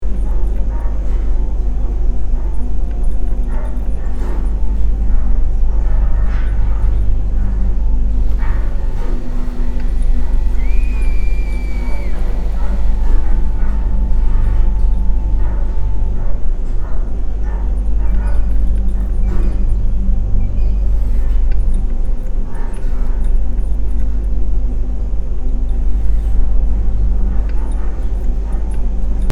Download Free Horror Sound Effects | Gfx Sounds
Horror-hospital-ambience-tension-loop.mp3